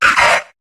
Cri de Cacnea dans Pokémon HOME.